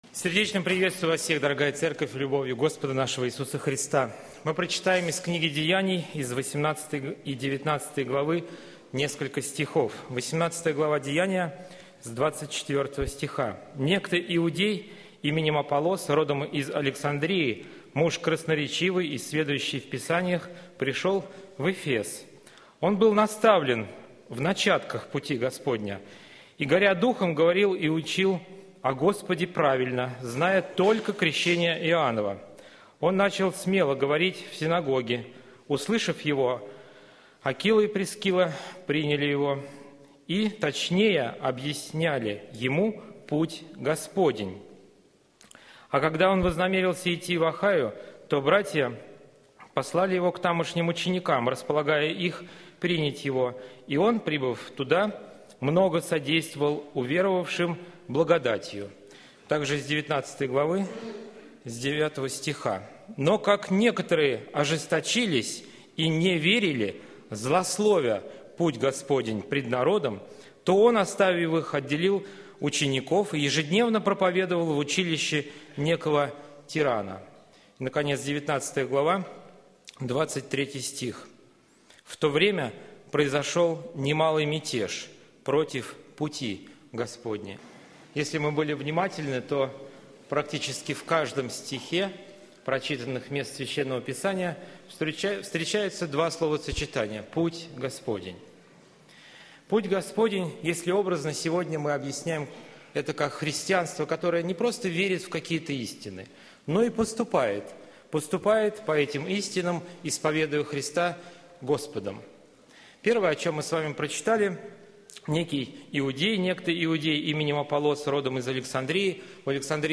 Церковь: Московская Центральная Церковь ЕХБ (Местная религиозная организация "Церковь евангельских христиан-баптистов г. Москвы")